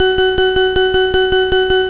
gs_extrapac.au